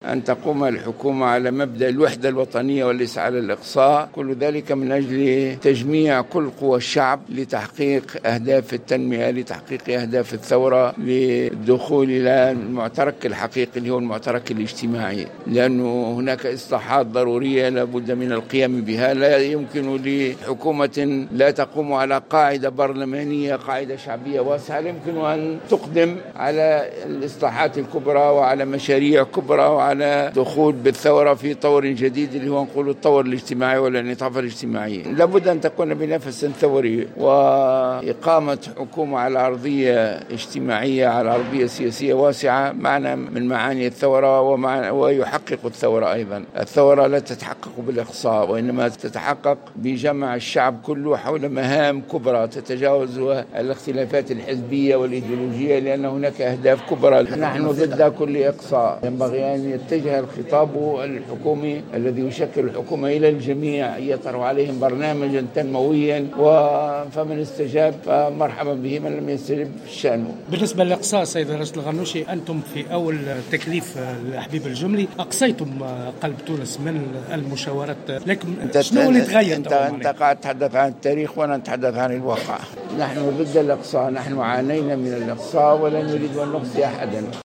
أكد رئيس مجلس نواب الشعب راشد الغنوشي في تصريح لمراسل الجوهرة "اف ام" اليوم الإثنين أن حركة النهضة ضد اقصاء أي طرف من المشاورات حول تشكيل الحكومة مشددا على ضرورة أن تقوم الحكومة المقبلة على مبدأ الوحدة الوطنية و ليس على قاعدة الإقصاء.